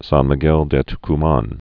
(sän mē-gĕl dĕ tk-män) or Tucumán